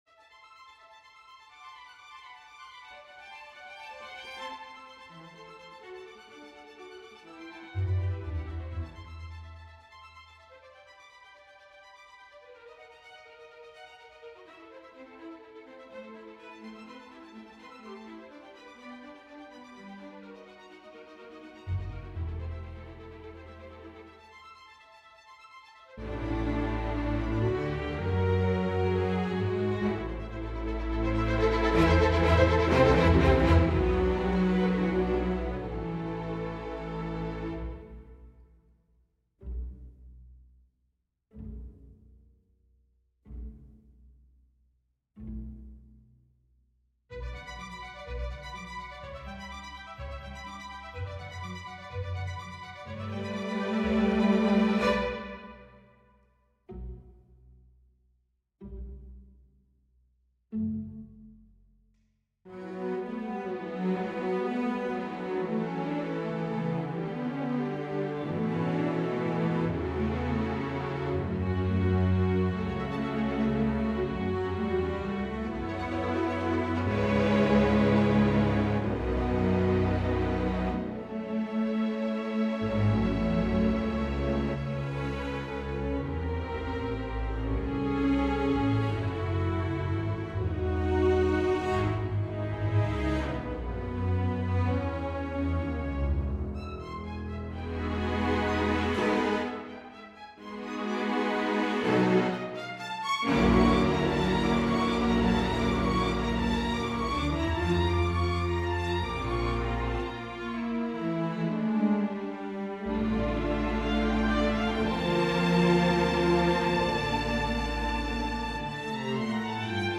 Event horizon - Orchestral and Large Ensemble - Young Composers Music Forum